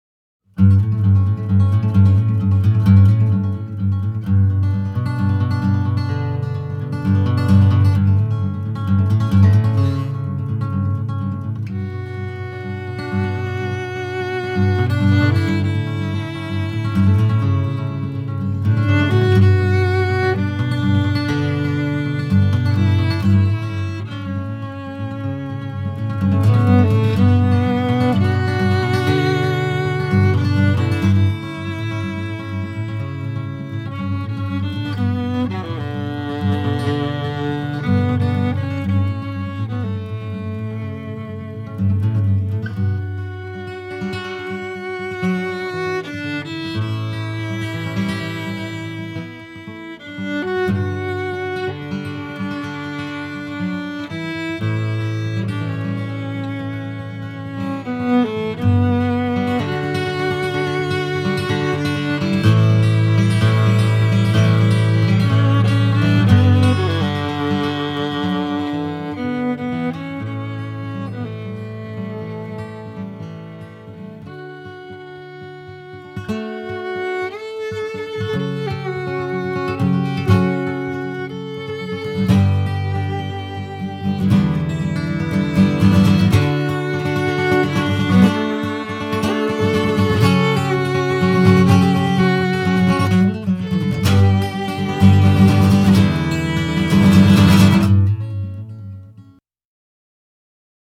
basement studio/bedroom